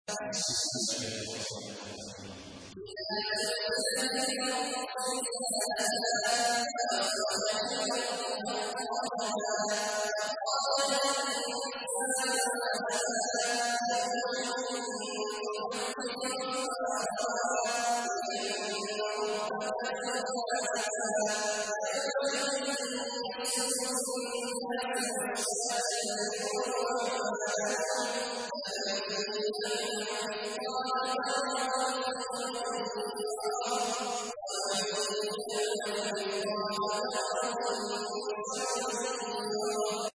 تحميل : 99. سورة الزلزلة / القارئ عبد الله عواد الجهني / القرآن الكريم / موقع يا حسين